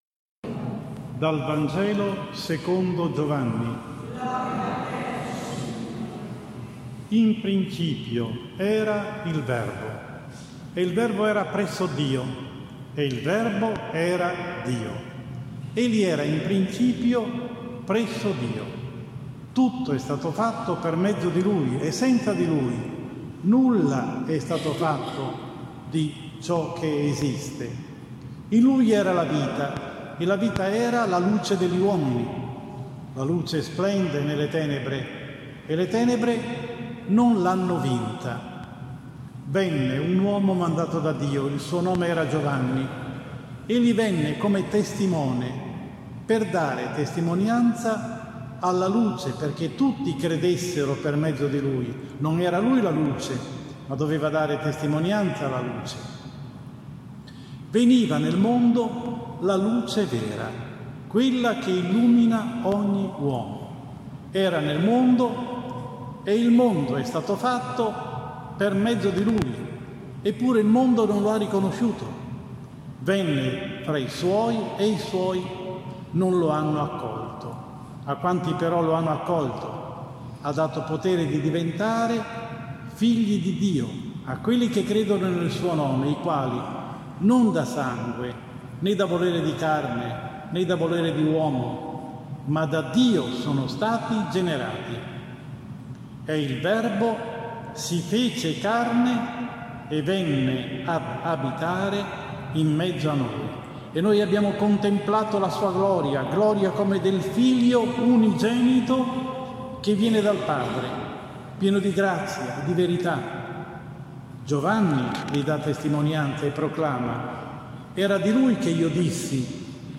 Seconda Domenica dopo Natale 05.01.2020 – omelia